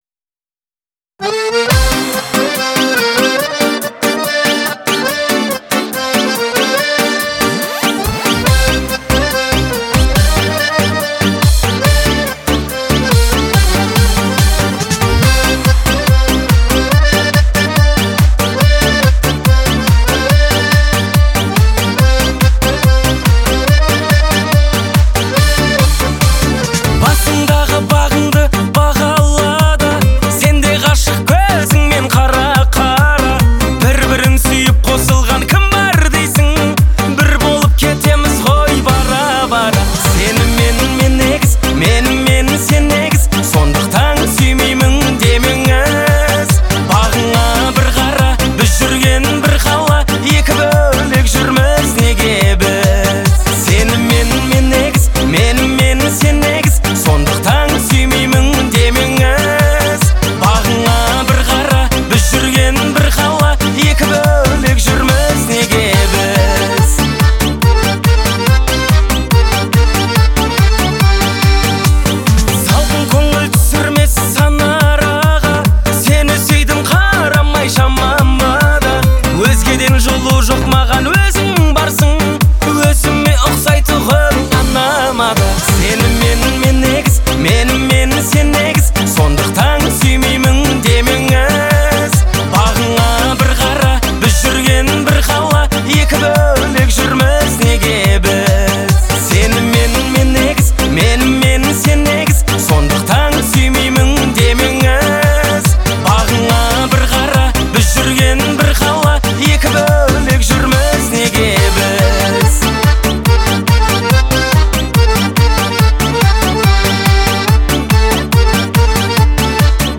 это трогательная песня в жанре казахского поп-фолка
нежный вокал и выразительные мелодии